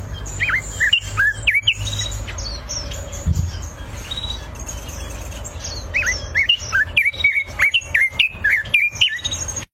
ringtone ave 7